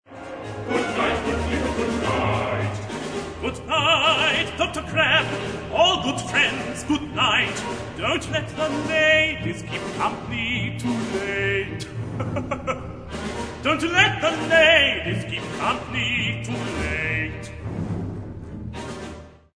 Discography – Opera Recordings
Part: Rev. Horace Adams